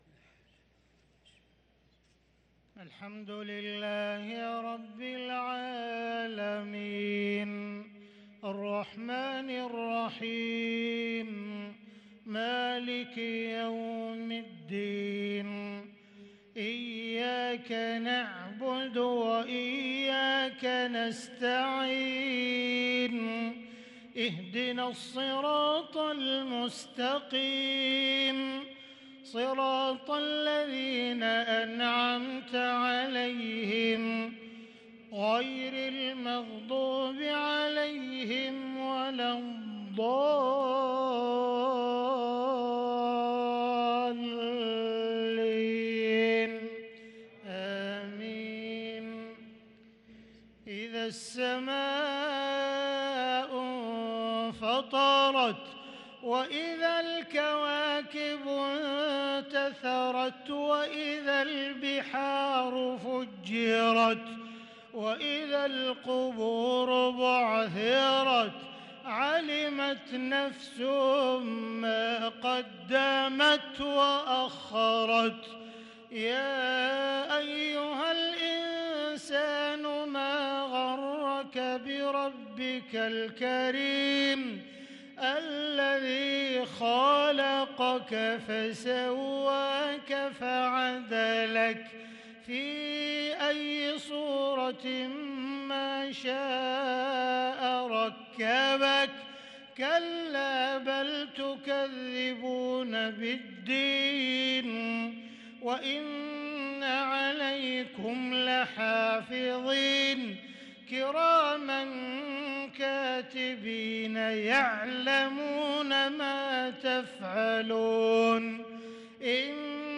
صلاة المغرب للقارئ عبدالرحمن السديس 22 صفر 1444 هـ
تِلَاوَات الْحَرَمَيْن .